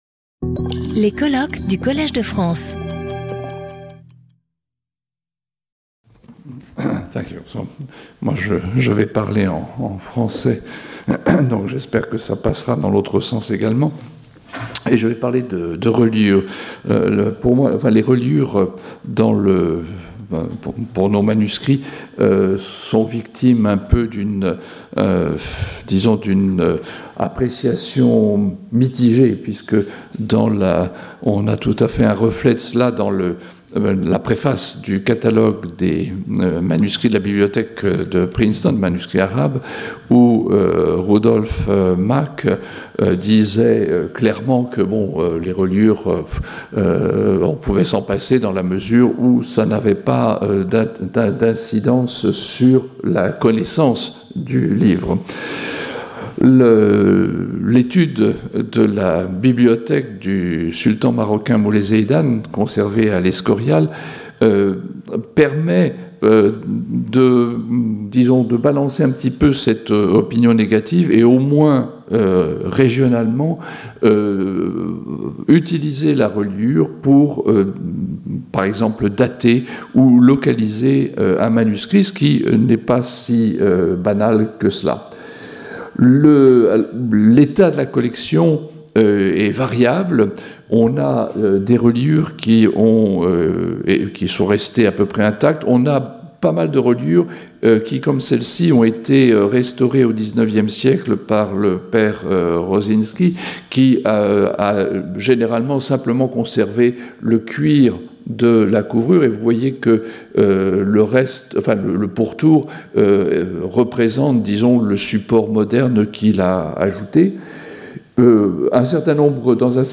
Colloque